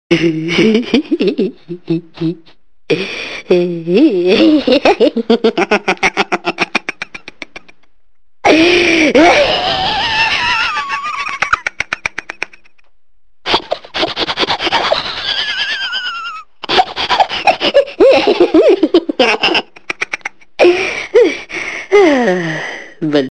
Komik